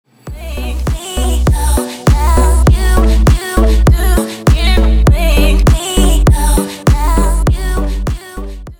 без слов
Короткий Инструментальный Проигрыш